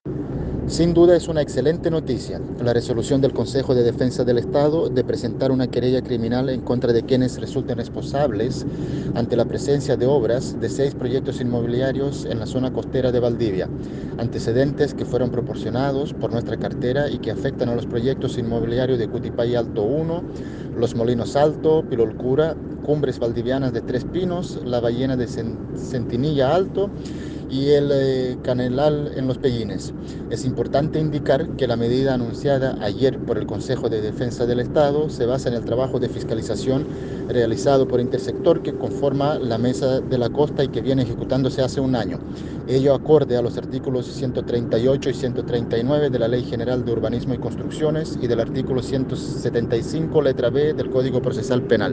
La medida anunciada ayer por el Consejo de Defensa del Estado se basa en el trabajo de fiscalización realizado por intersector que conforma la Mesa de la Costa y que viene ejecutándose hace un año, ello acorde a los artículos 138 y 139 de la Ley General de Urbanismo y Construcciones y el articulo 175 letra b) del Código Procesal Penal; así lo explicó el seremi Feda Simic.
CUNA-SEREMI-VIVIENDA-Y-URBANISMO.ogg